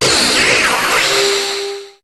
Cri de Necrozma dans Pokémon HOME.